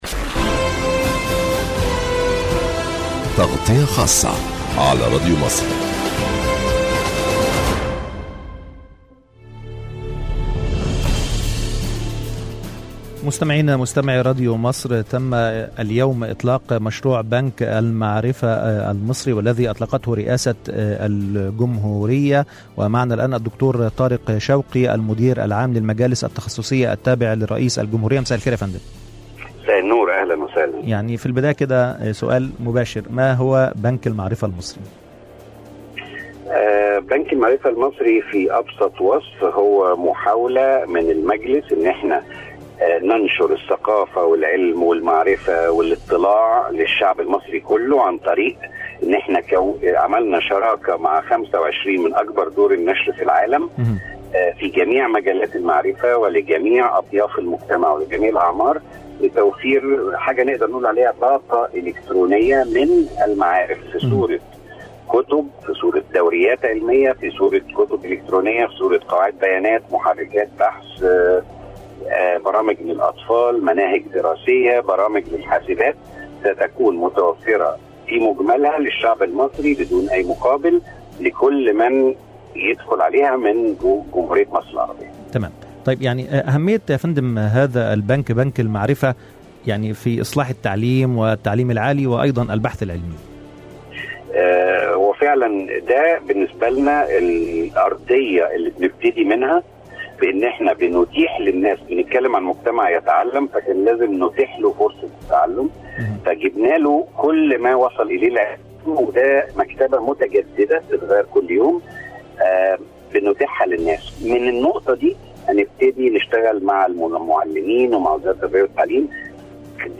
للإستماع لتصريحات الدكتور طارق شوقي الأمين العام للمجالس التخصصية التابع لرئاسة الجهورية علي  راديو مصر